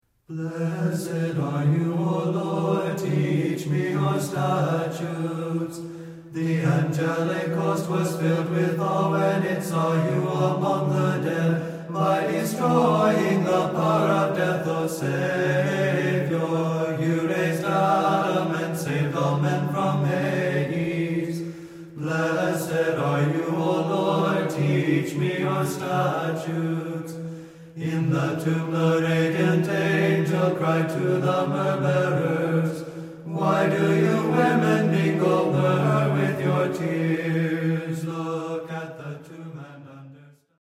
Byzantine--Tone 5